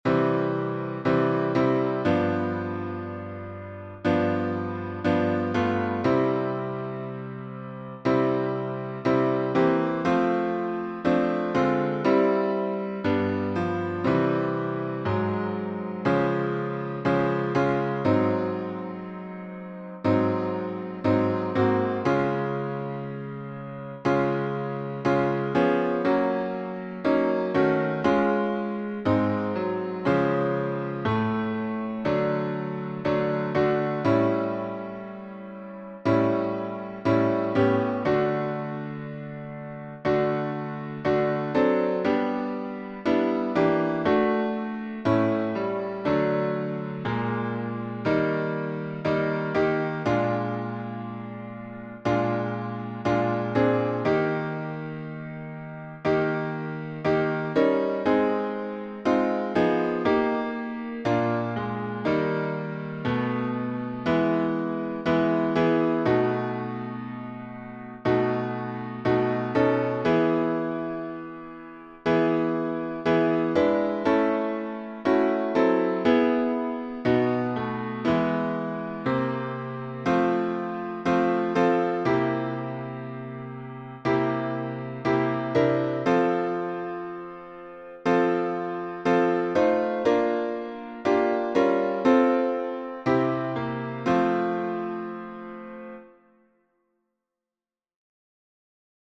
#5051: God Is So Good — with key changes | Mobile Hymns